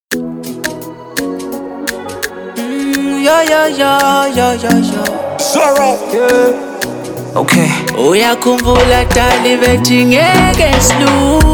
Afro House track